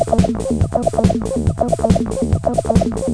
CR - Drums es una caja de ritmos, en la que he seleccionado los sonidos de percusión que suelen gustar, y tomando como base los diseños de las magníficas TR 606, 808 y 909; se han construido siete pasos analógicos, bass, snare, hi hats, toms y rim.
CB-303 y CRDrums al mismo tiempo